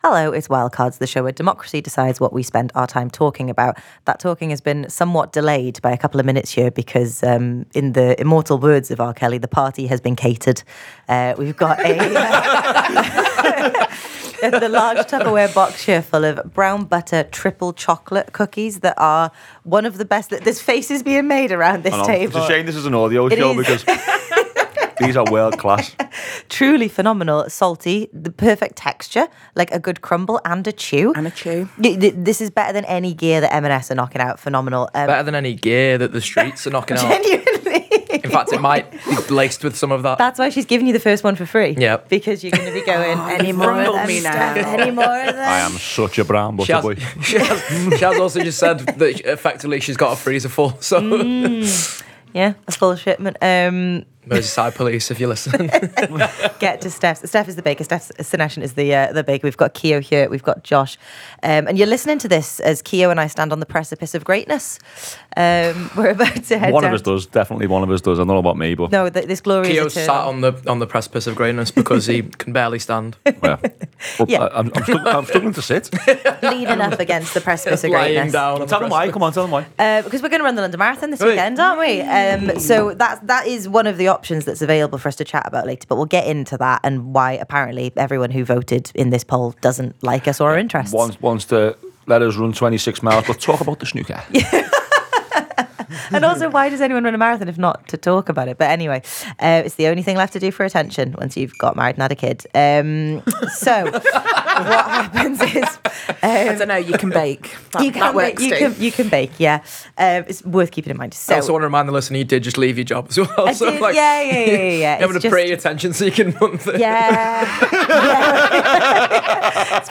Below is a clip from the show – subscribe for more on the popular topics of the week…